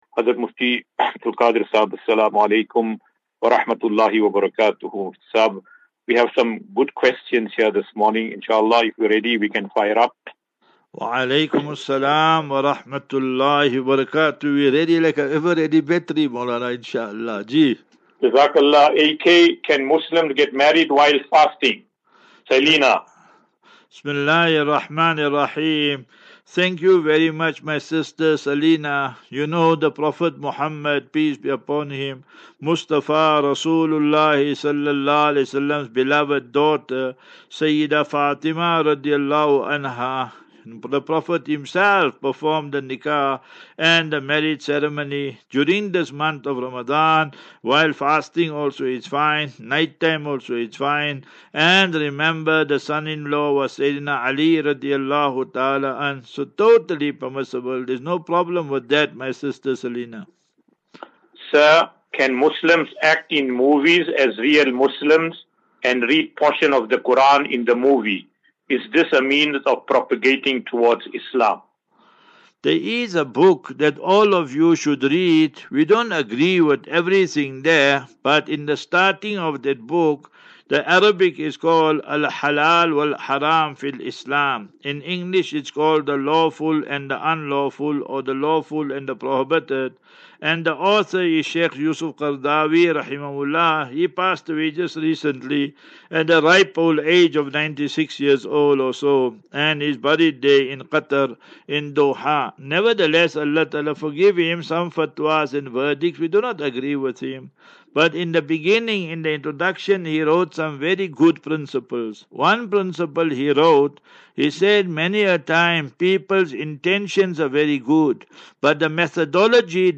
As Safinatu Ilal Jannah Naseeha and Q and A 14 Mar 14 March 2024.